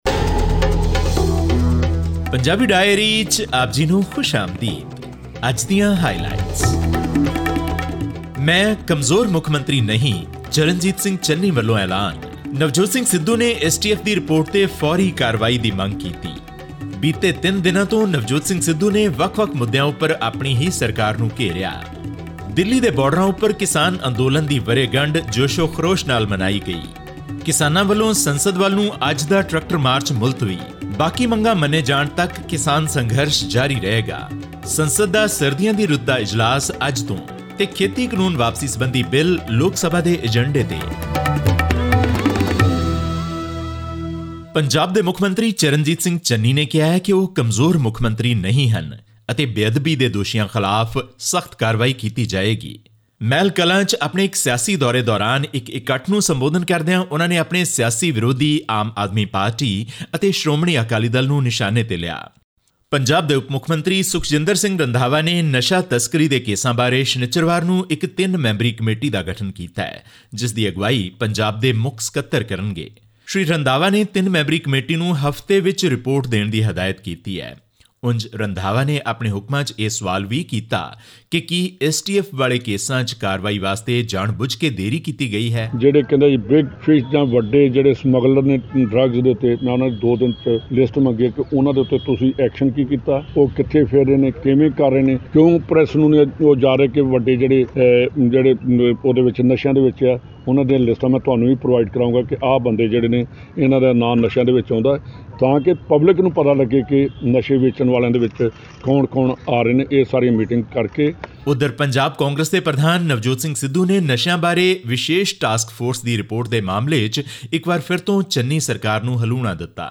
Hundreds of farmers gathered at the Singhu, Tikri and Ghazipur borders around the national capital on 26 November to mark one year of the protests against three farm laws that Prime Minister Narendra Modi announced to repeal earlier this month. This and more in our weekly news update.